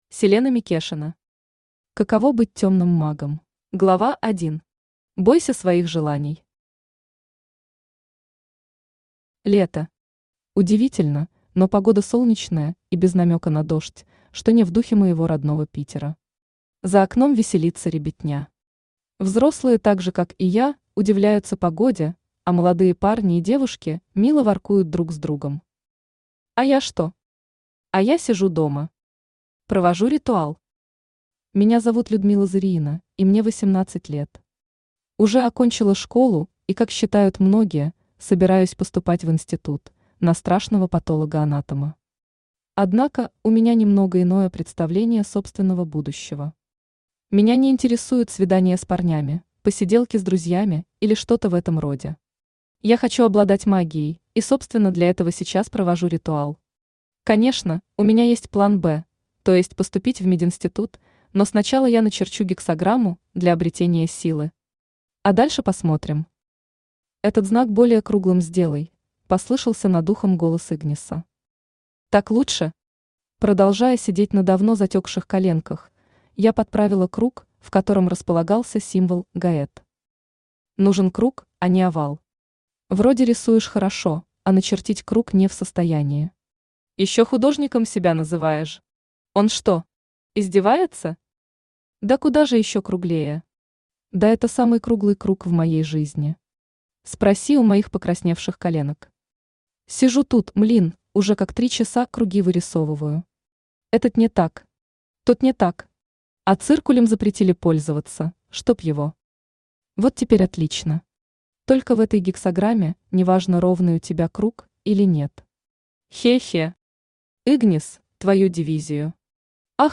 Аудиокнига Каково быть темным магом | Библиотека аудиокниг